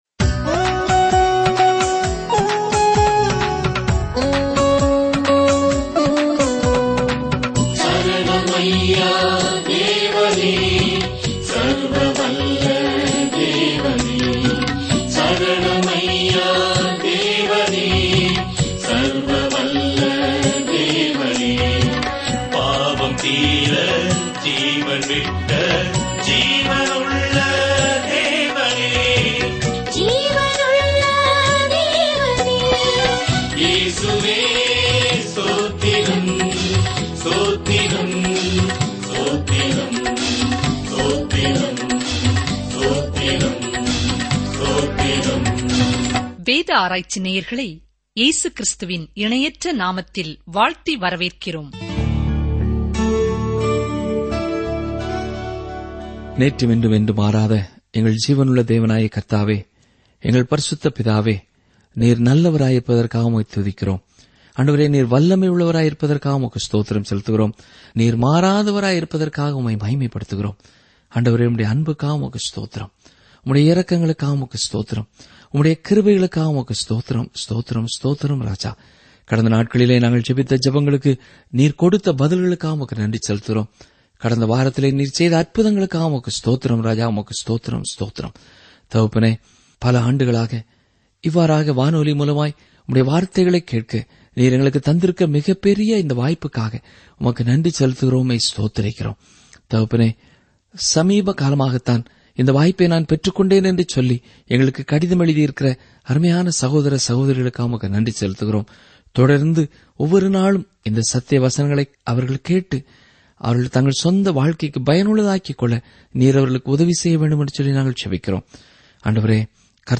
வேதவசனங்கள் எசேக்கியேல் 2 நாள் 2 இந்த திட்டத்தை ஆரம்பியுங்கள் நாள் 4 இந்த திட்டத்தைப் பற்றி கடவுளிடம் திரும்பும்படி எசேக்கியேலின் எச்சரிக்கும் வார்த்தைகளுக்கு மக்கள் செவிசாய்க்கவில்லை, அதற்கு பதிலாக அவர் அபோகாலிப்டிக் உவமைகளை நடித்தார், அது மக்களின் இதயங்களைத் துளைத்தது. நீங்கள் ஆடியோ படிப்பைக் கேட்கும்போதும் கடவுளுடைய வார்த்தையிலிருந்து தேர்ந்தெடுக்கப்பட்ட வசனங்களைப் படிக்கும்போதும் எசேக்கியேல் வழியாக தினசரி பயணம் செய்யுங்கள்.